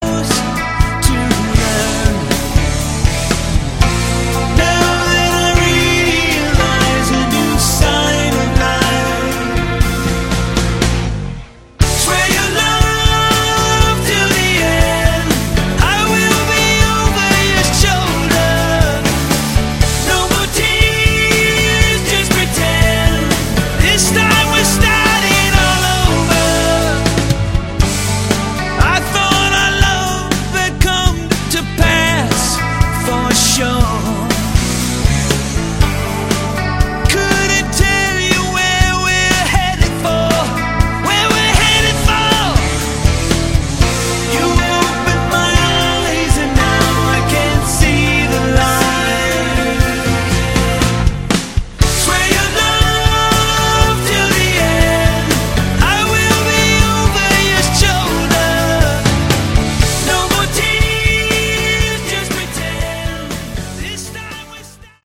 Category: AOR
Vocals
Guitars